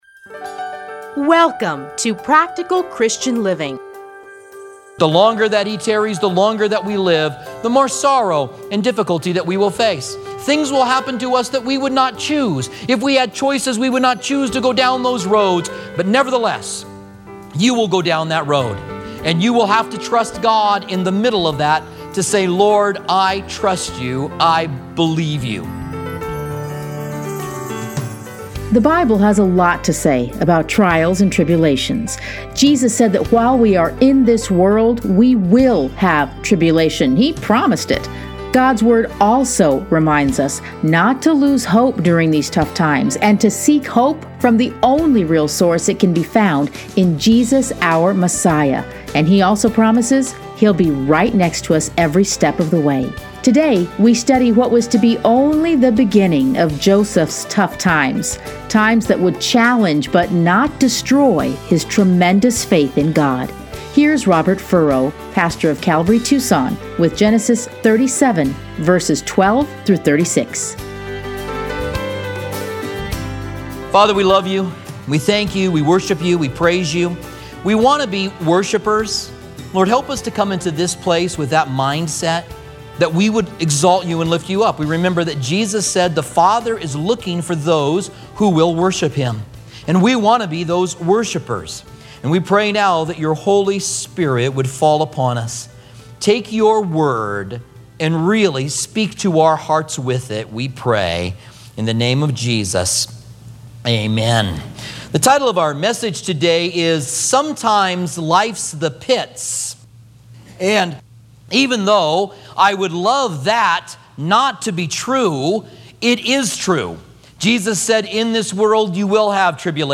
Listen here to a teaching from Genesis.